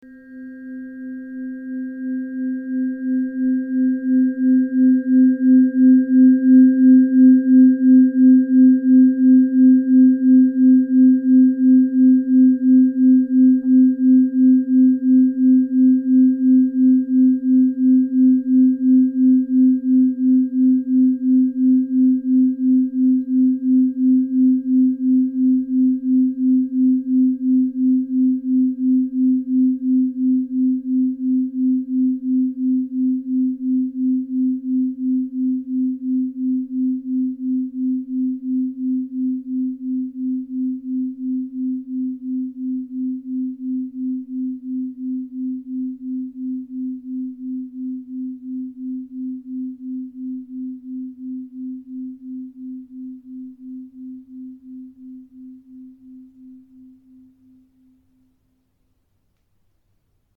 brain-tuner-theta.mp3